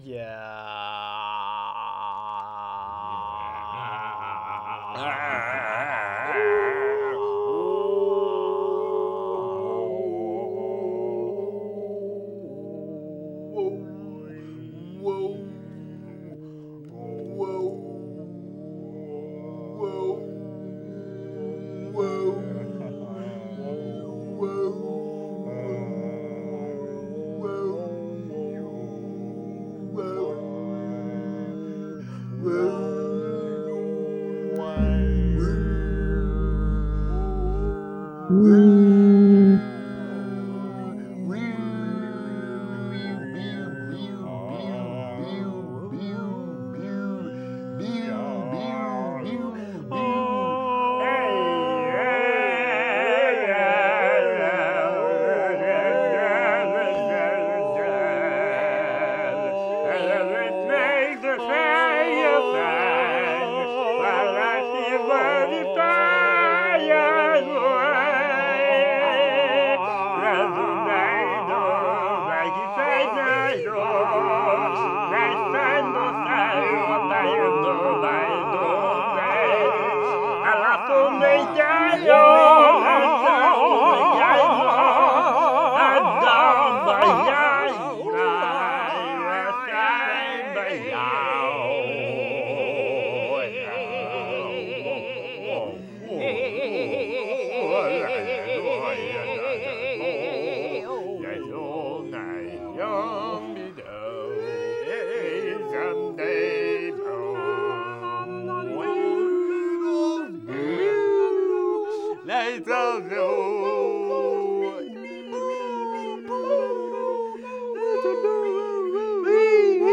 In the meantime, check out these MP3s of the weird yelling from the end of last week’s show.
Weird Chanting
weirdchanting.mp3